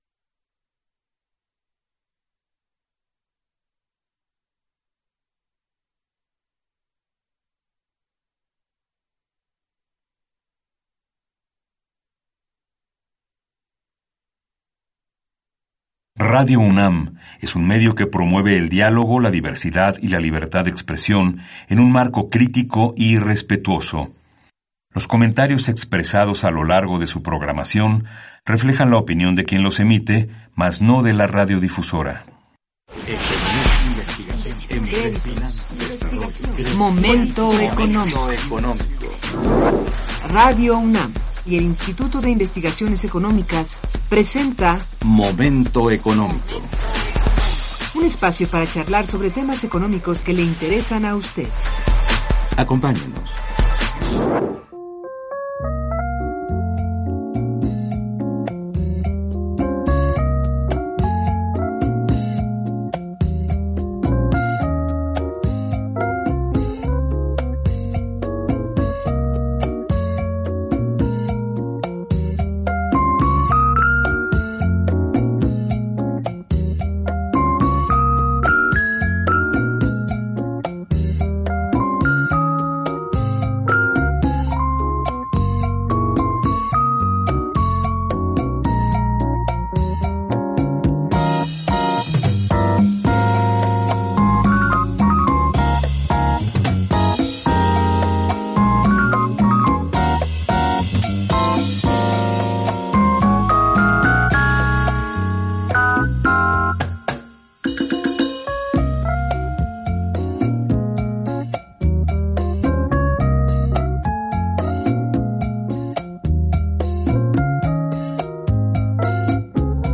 Las invitadas hablaron de los productos mexicanos que con mucho esfuerzo y trabajo han logrado el sello de Denominación de Origen, de acuerdo a las indicaciones geográficas de donde son originarios. Se refirieron al procedimiento que esto implica y cuáles son las zonas geográficas de nuestro país que reúnen condiciones a ser consideradas para obtener el sello, sus productos, de Denominación de Origen.